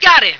flak_m/sounds/female2/int/F2gotim.ogg at fd5b31b2b29cdd8950cf78f0e8ab036fb75330ca